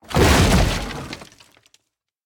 destroy1.ogg